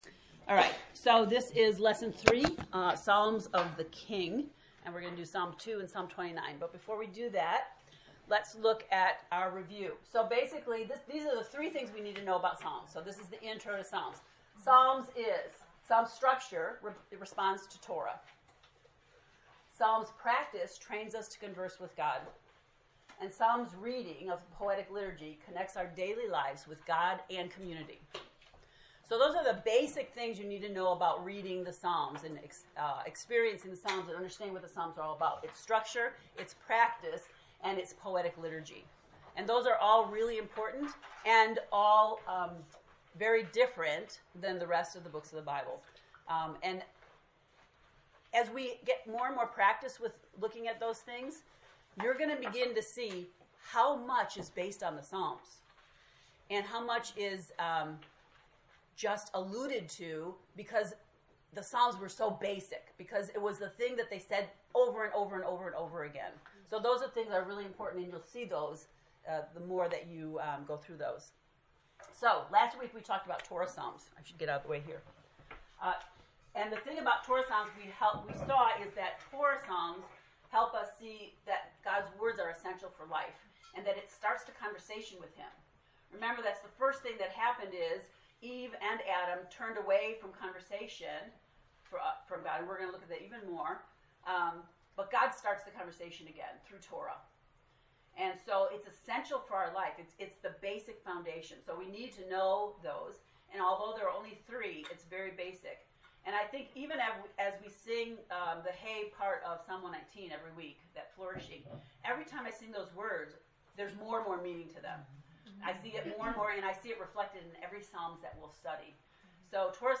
To listen to the lecture, “Psalms of the King,” click below:
psalms-lect-3.mp3